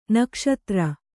♪ nakṣatra